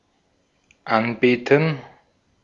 Ääntäminen
IPA : /əˈdɔːr/